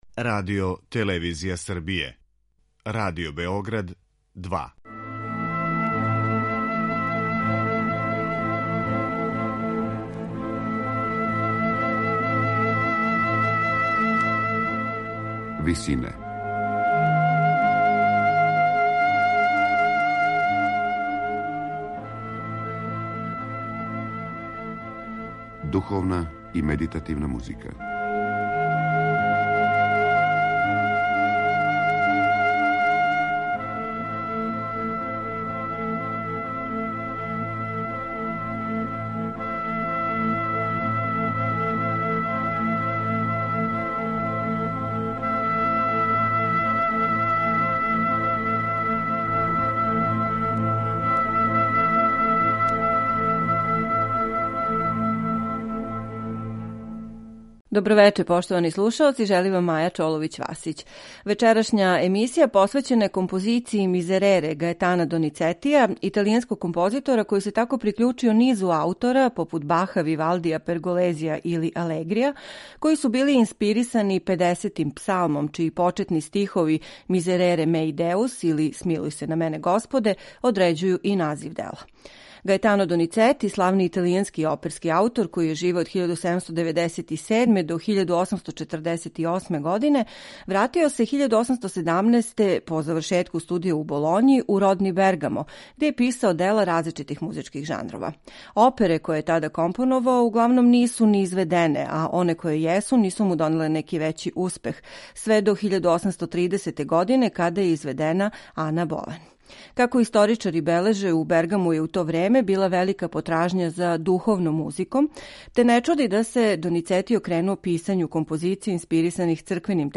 медитативне и духовне композиције
У данашњој емисији слушаћете снимак хора и оркестра Словачке филхармоније